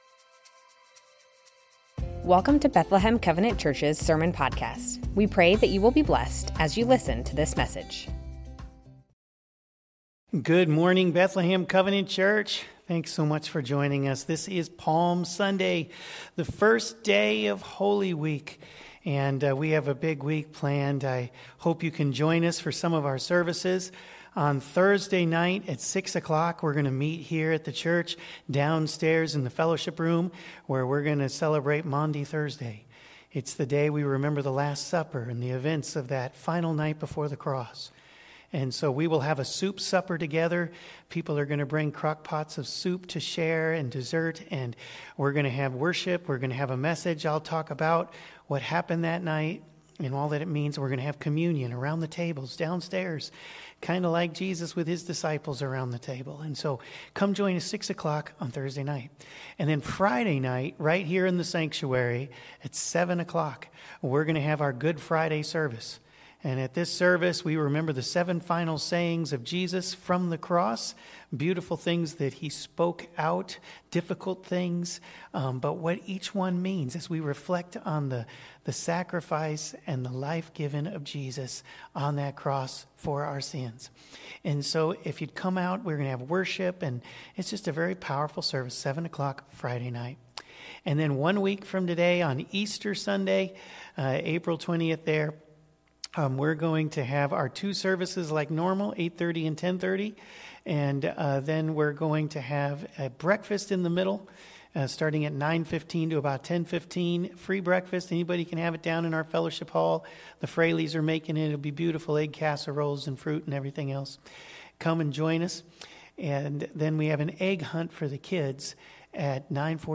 Bethlehem Covenant Church Sermons Palm Sunday Apr 14 2025 | 00:28:55 1x 00:00 / 00:28:55 Subscribe Share Spotify RSS Feed Share Link Embed